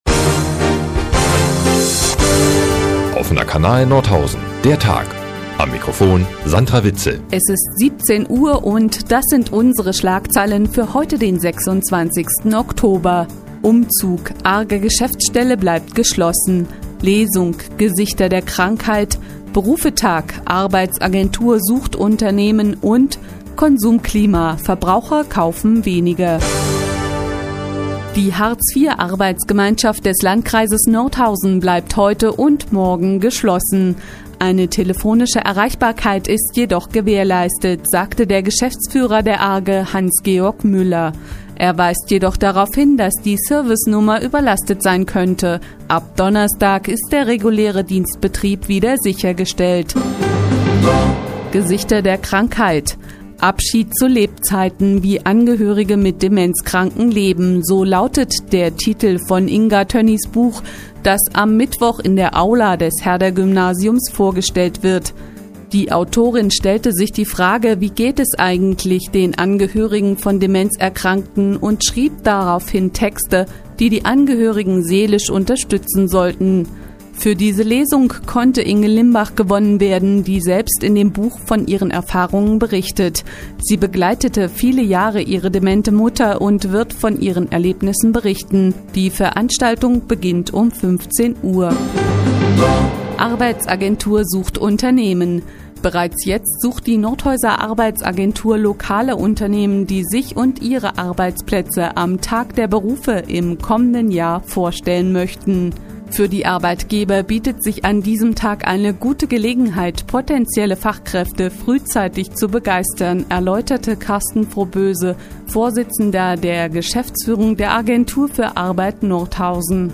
Die tägliche Nachrichtensendung des OKN ist nun auch in der nnz zu hören. Heute geht es unter anderem um ihre Kauflaune und eine Buchvorstellung.